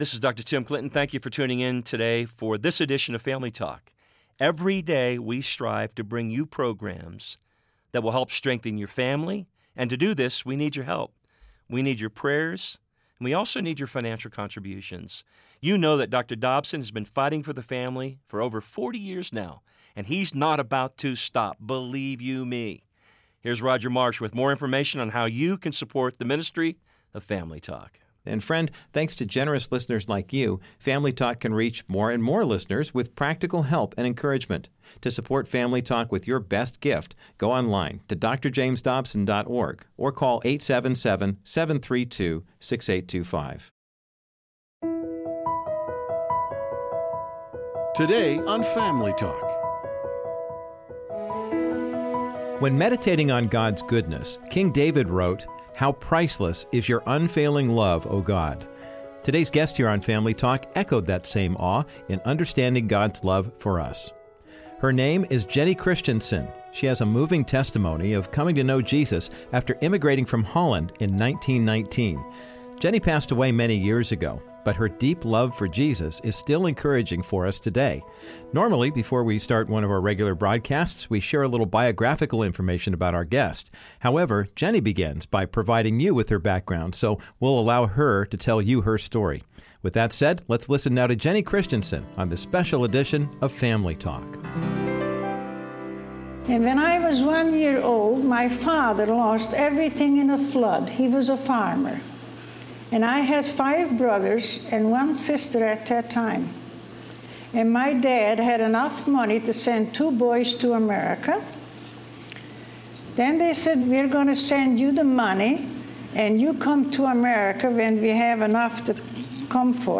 On this classic broadcast of Family Talk